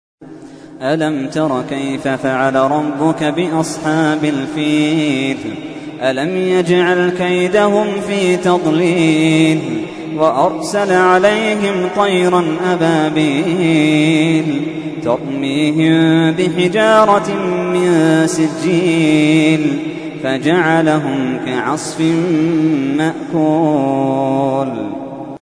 تحميل : 105. سورة الفيل / القارئ محمد اللحيدان / القرآن الكريم / موقع يا حسين